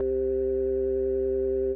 Organ - Virtual.wav